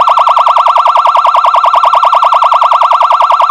gen_2_phsr.wav